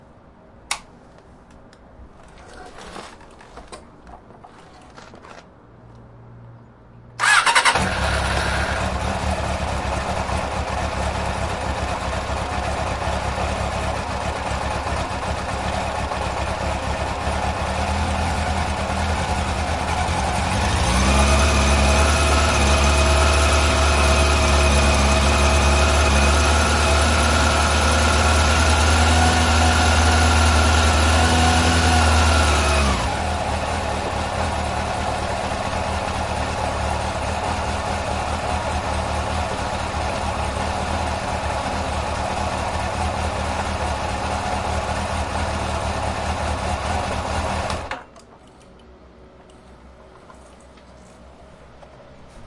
小飞机点火
描述：启动小型铝制飞机的引擎。变焦H4，设置在44.1，内置麦克，高麦克增益。飞机大约在100英尺以外。
标签： 发动机 H4 点火器 飞机 变焦 开始 向上 平面
声道立体声